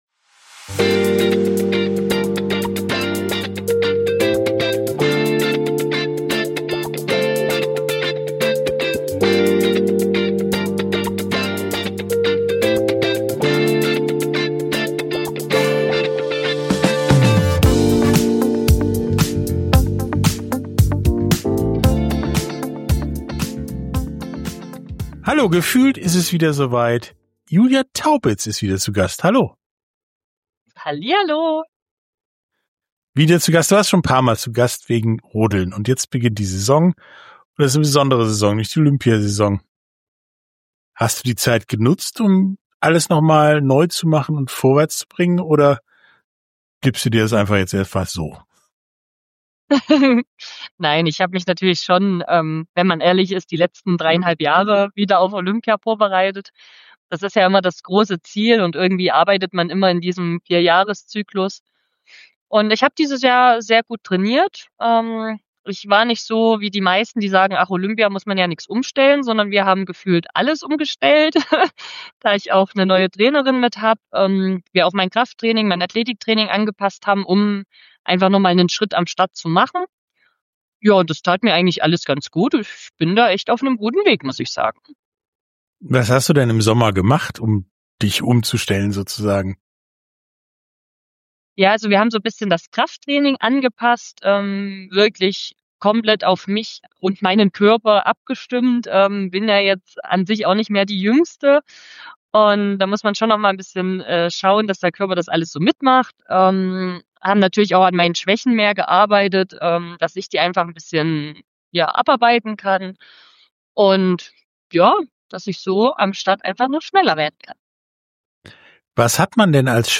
Sportstunde - Interview mit Julia Taubitz – Rodeln ~ Sportstunde - Interviews in voller Länge Podcast